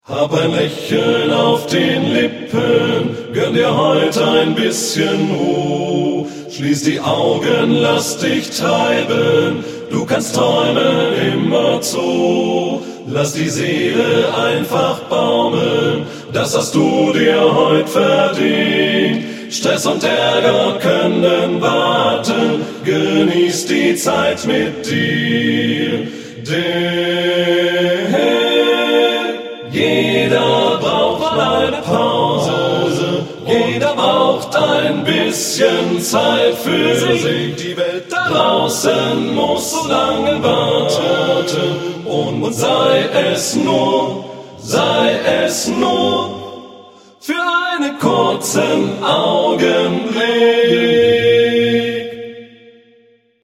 Ein Lied zum Verschnaufen, lustig und leicht!